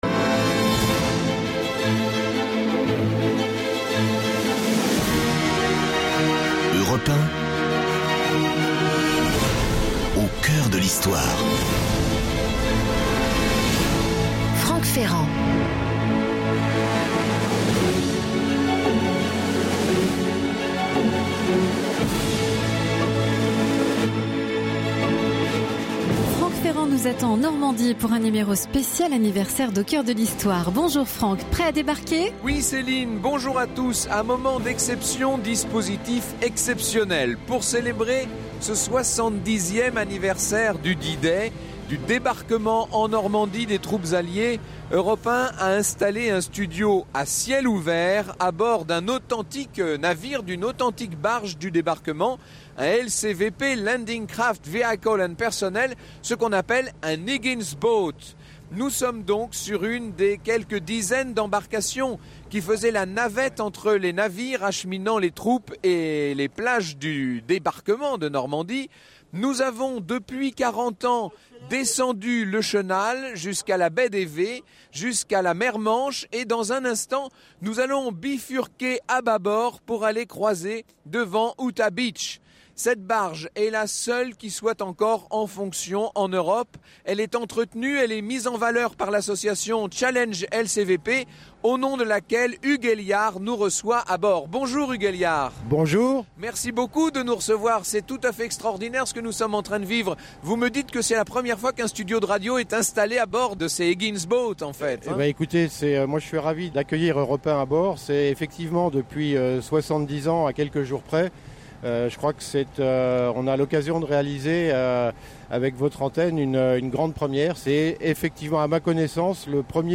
CHALLENGE L.C.V.P. - studio Europe 1 � bord du PA30-4